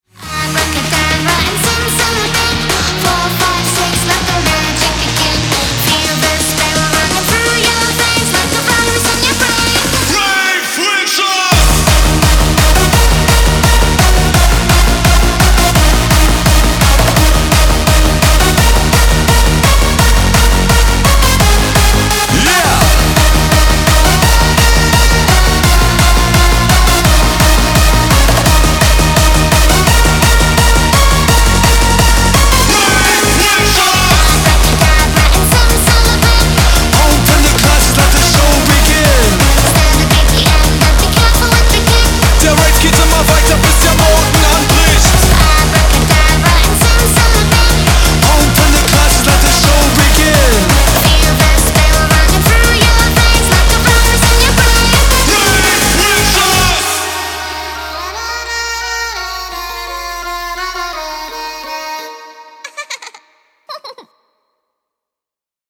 • Качество: 320, Stereo
позитивные
зажигательные
веселые
dance
EDM
нарастающие
Big Room
mash up
Зажигательный и весёлый клубняк ;)